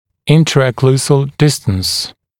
[ˌɪntərə’kluːzəl ‘dɪstəns] [-səl][ˌинтэрэ’клу:зэл ‘дистэнс] [-сэл]межокклюзионный промежуток